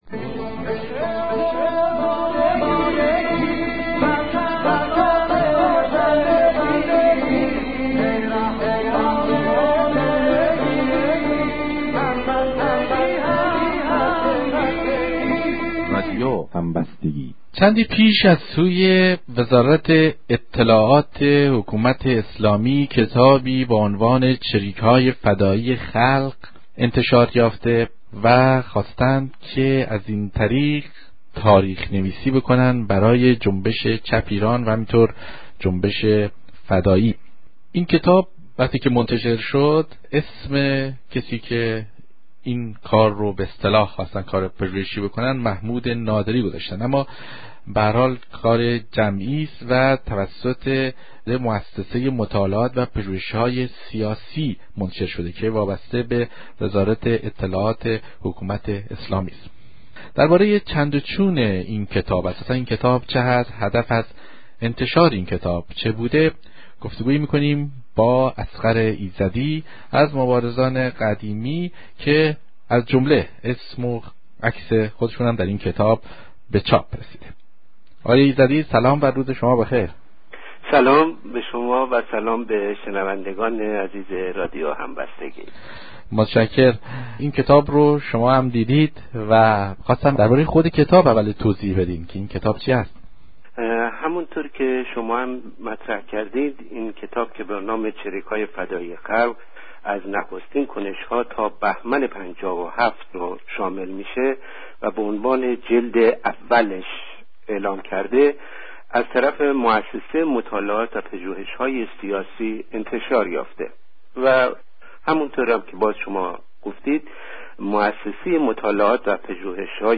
مشروح گفت و گو